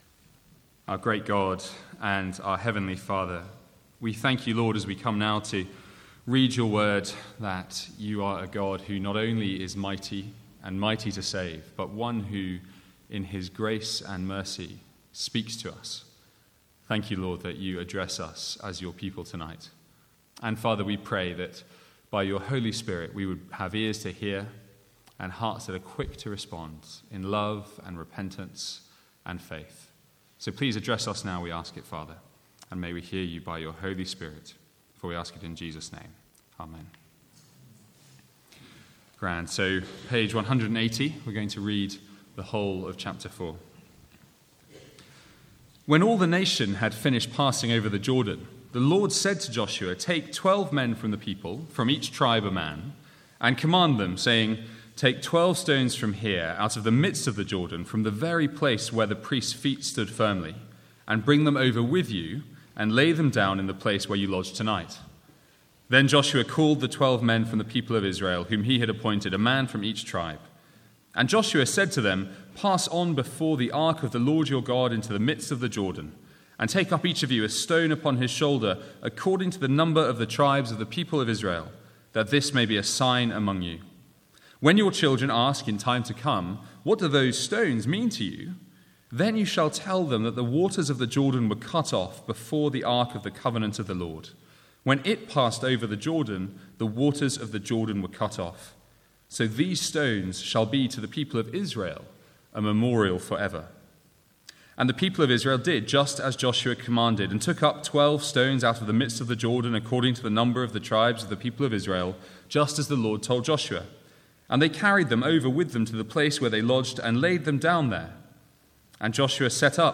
Sermons | St Andrews Free Church
From the Sunday evening series in Joshua.